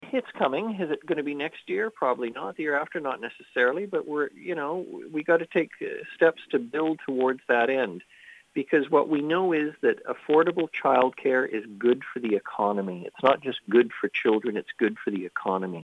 Nanaimo MLA, Leonard Krog says there’s no announcement yet on 10 dollar a day daycare, but it will come…..